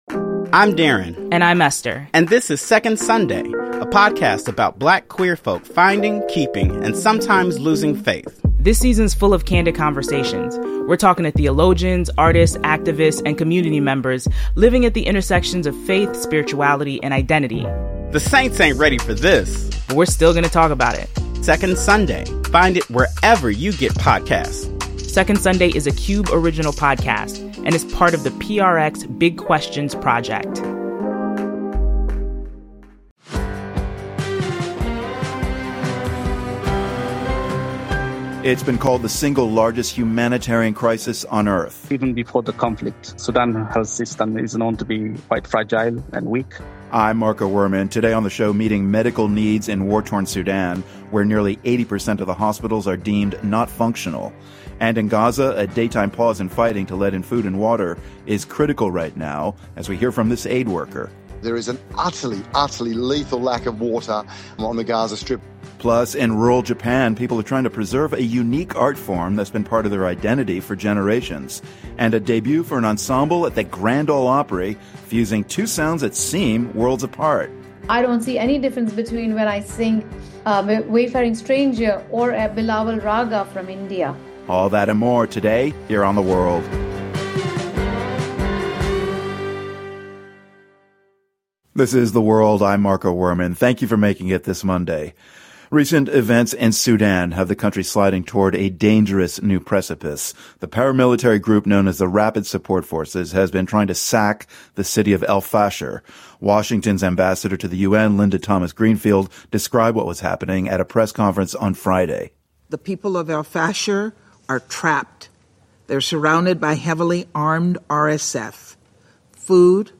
A UNICEF representative based in Gaza joins us from inside the territory with insights on what a proposed pause in fighting would — and wouldn't — accomplish on the ground. Plus, in a diplomatic shakeup, China is recalling two of its diplomats to Australia.